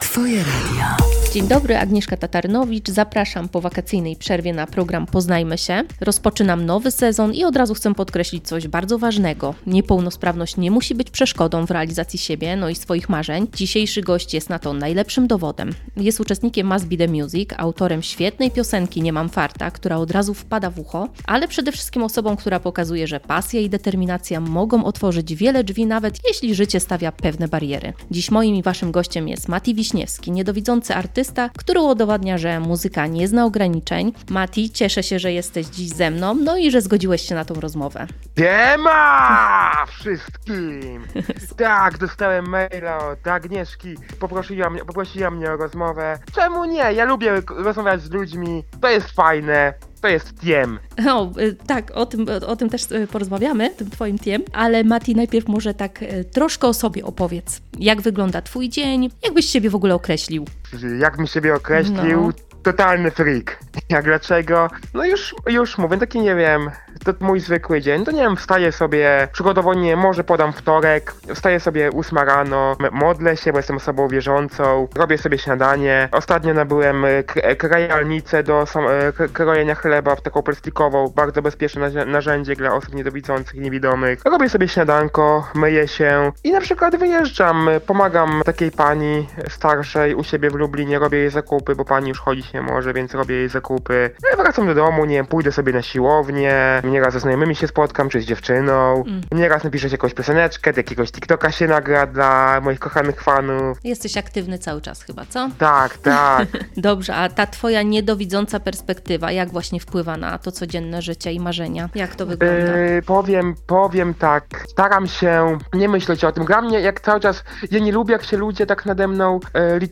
Rozmowa poświęcona jest pasji, determinacji i sile marzeń, które nie znają granic.